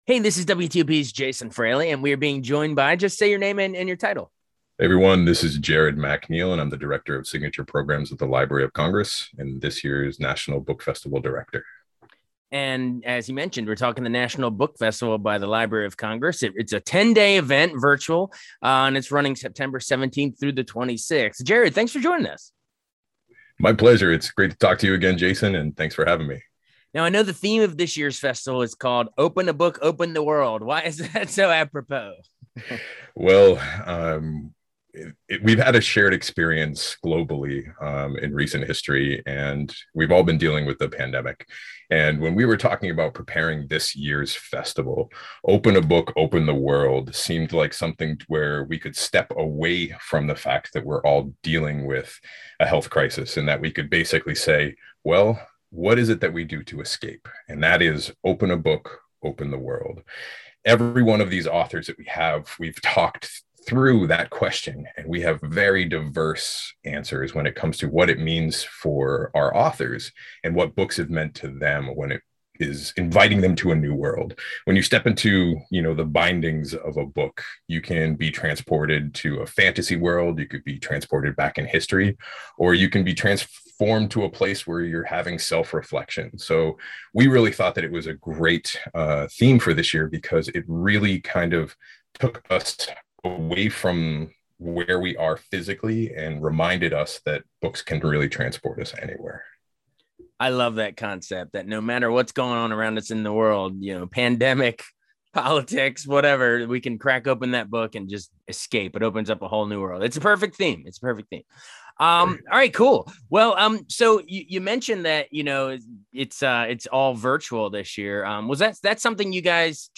book-fest-interview.mp3